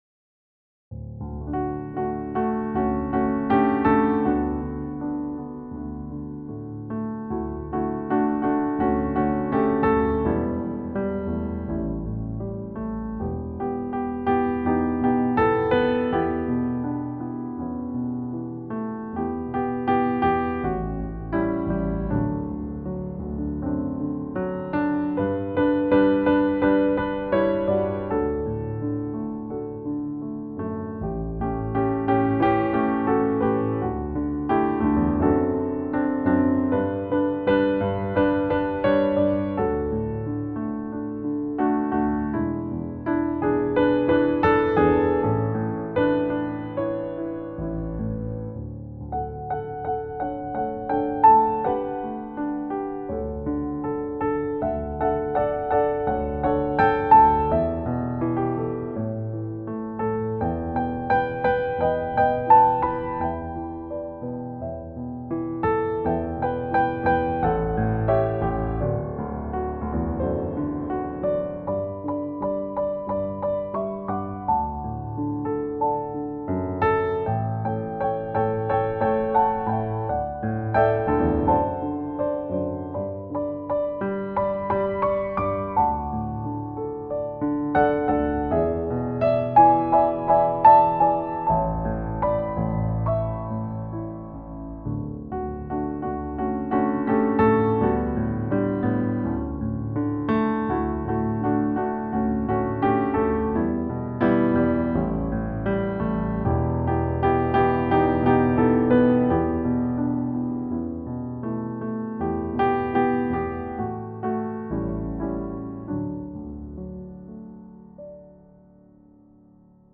Praise Band At Home
Whilst we are unable to be together to play, the praise band and friends have been recording for the message each week.